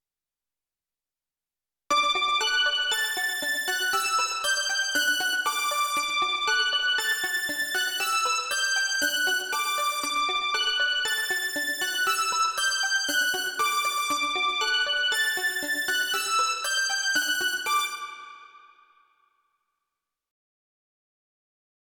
118 BPM
Heroic travel theme
D major triumphant lead over running D/G arpeggio